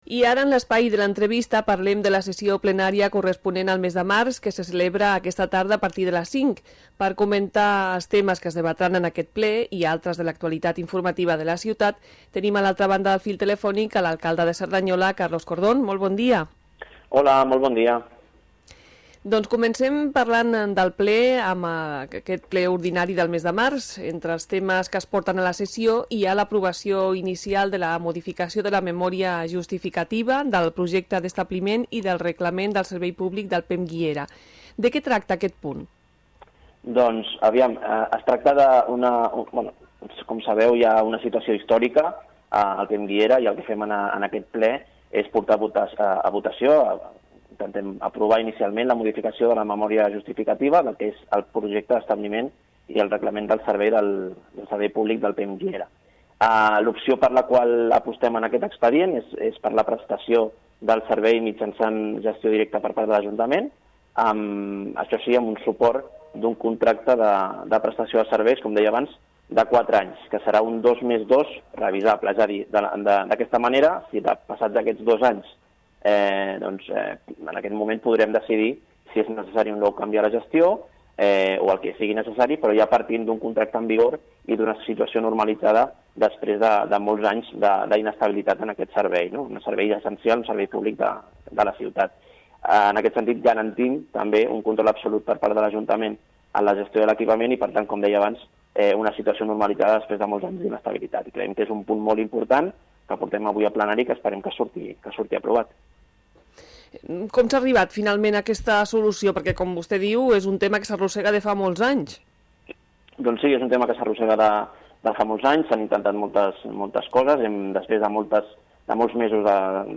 Entrevista-Carlos-Cordón-prèvia-Ple-març.mp3